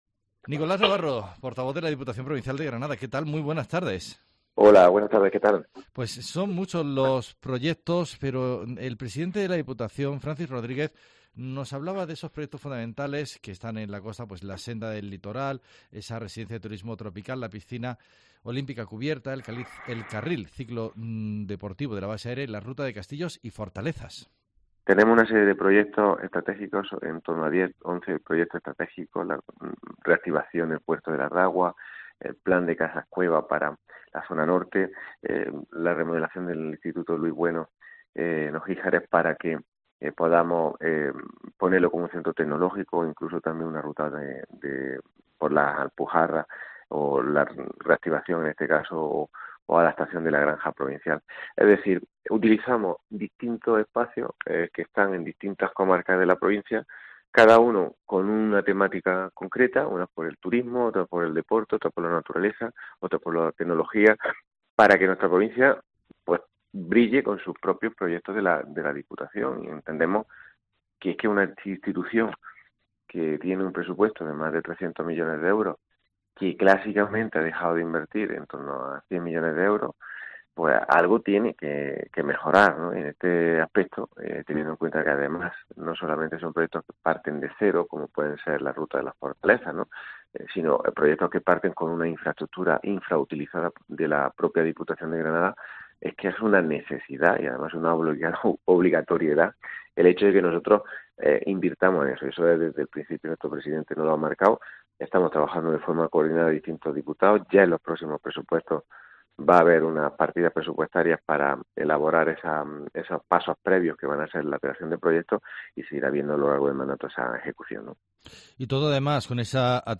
Informativo Cope Guadix 1420h 27 Octubre